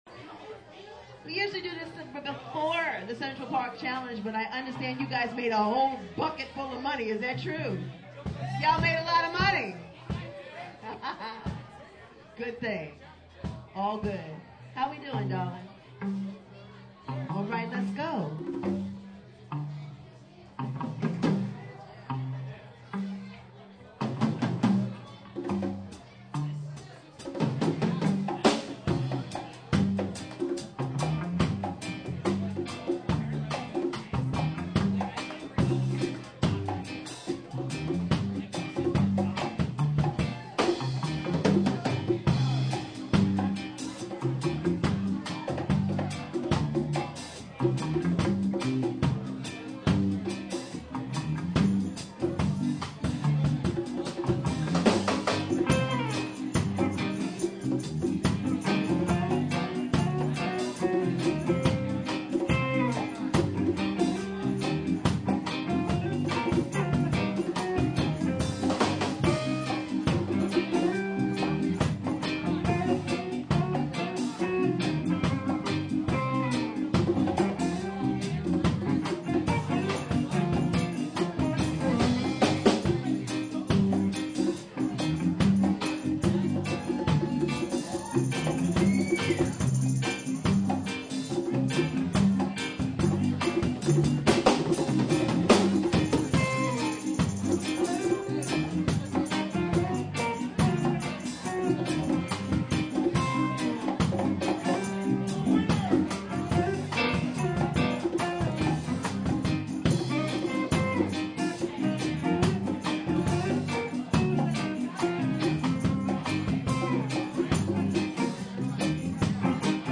guitar and vocals
keyboards
percussion and vocals
drums